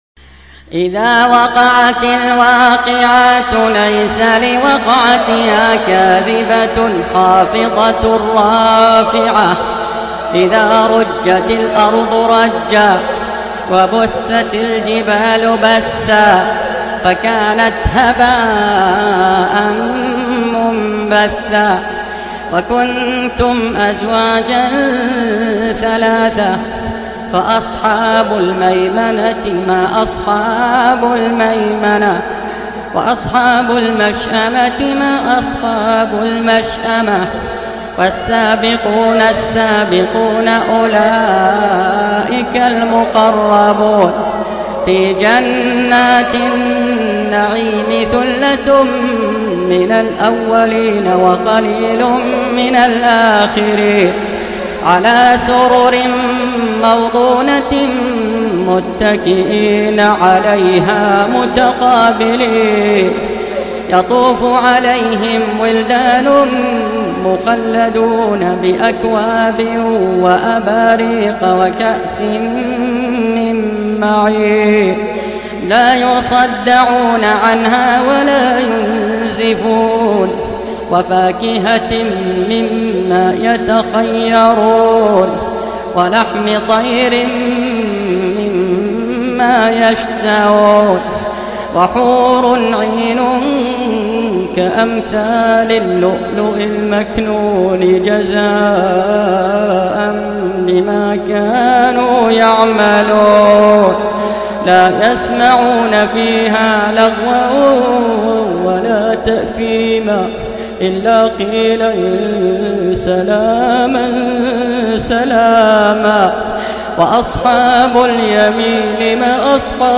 56. Surah Al-W�qi'ah سورة الواقعة Audio Quran Tarteel Recitation
Surah Repeating تكرار السورة Download Surah حمّل السورة Reciting Murattalah Audio for 56.